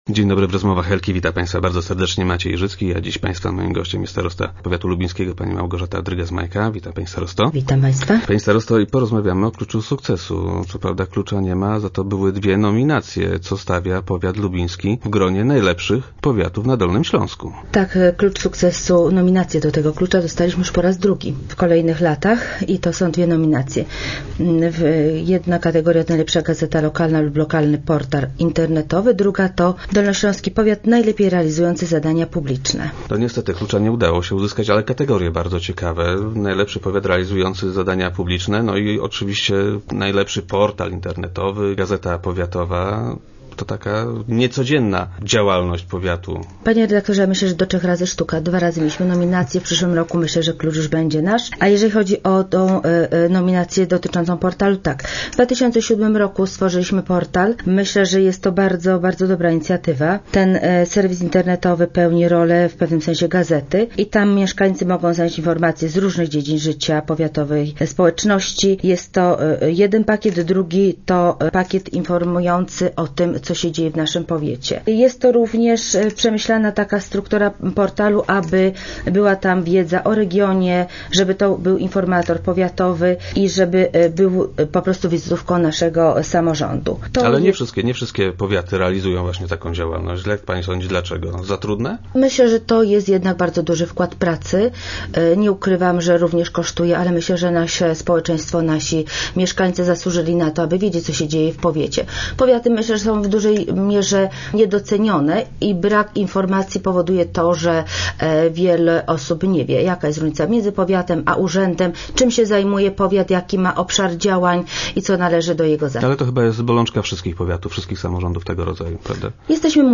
Start arrow Rozmowy Elki arrow Drygas - Majka: Klucz będzie nasz w przyszłym roku
- Choć klucza nie dostaliśmy, to same nominacje są dla nas bardzo ważne - powiedziała nam Małgorzata Drygas - Majka, starosta powiatu lubińskiego.